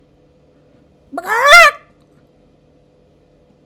Category 🐾 Animals
bird bwack chicken Chicken cluck loud scream squawk sound effect free sound royalty free Animals